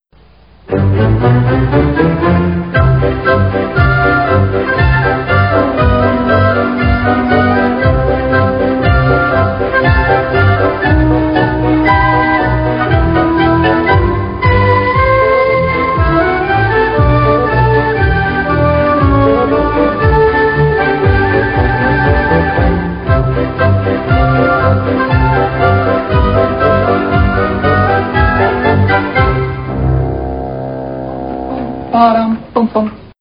Theme Song (WAV 5.9 MB)